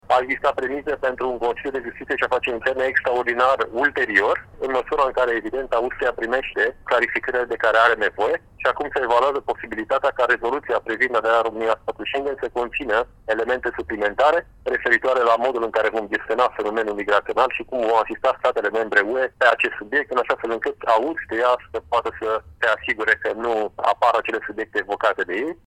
Dacă Austria nu va ceda, România mai are și planuri de rezervă, explică eurodeputatul Victor Negrescu:
07dec-13-Negrescu-variantele-de-dupa.mp3